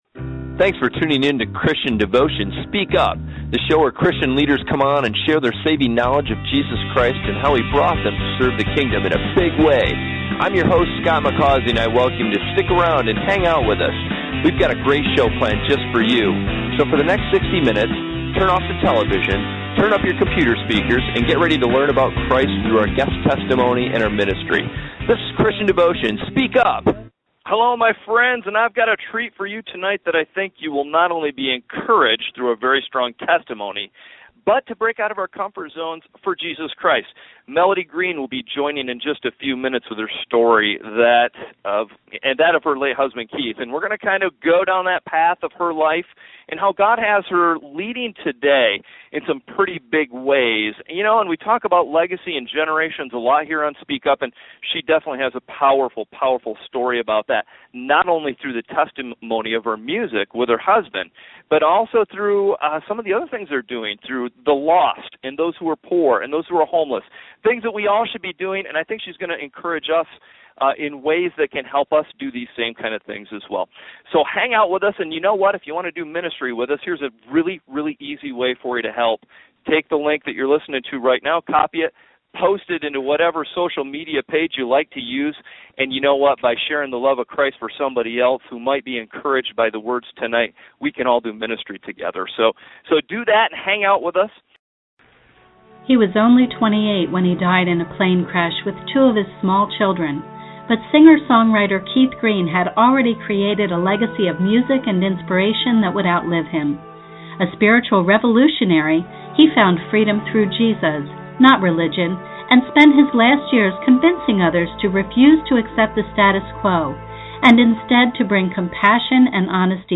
An Interview with Melody Green - Theology Mix